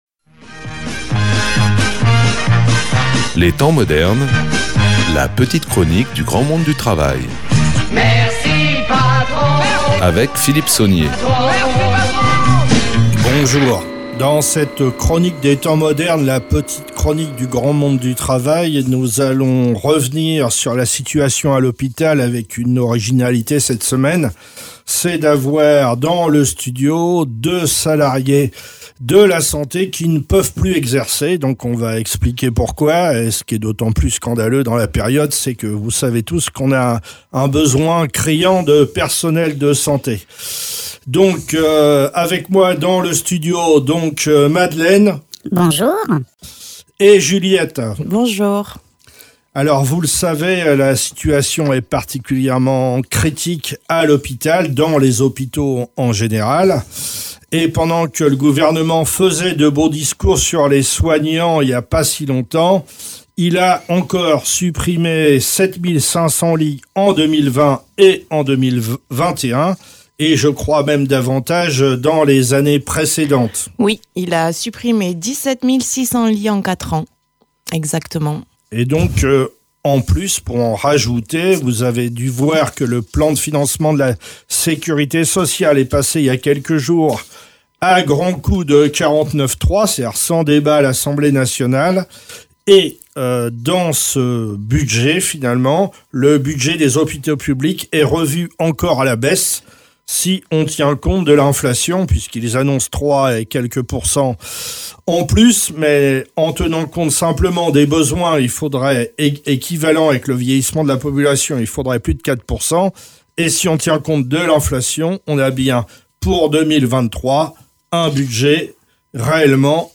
Interview du personnel soignant interdit d’exercer à l’hôpital, examen de cette volonté d’exclusion dans une période où les besoins en professionnels de santé sont si criants.